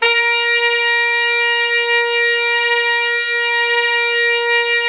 SAX_sfa#4x   243.wav